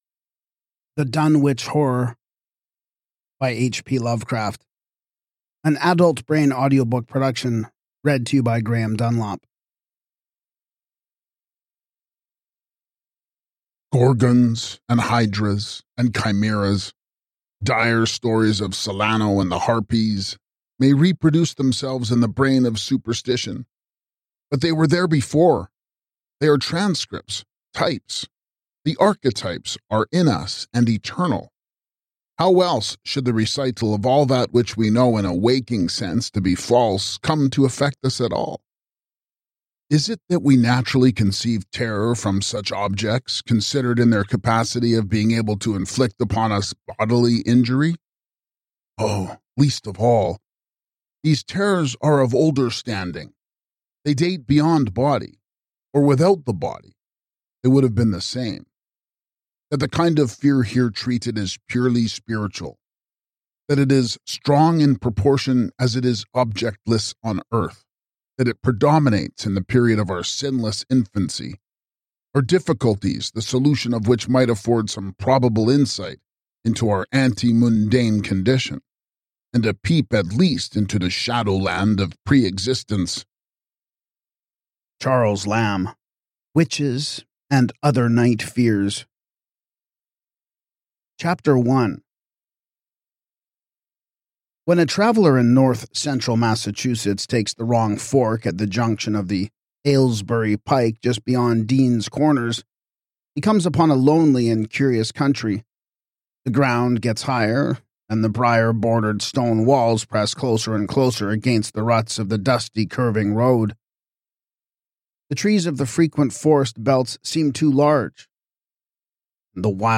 With atmospheric narration and immersive sound, this audiobook brings Lovecraft’s eerie vision to life like never before.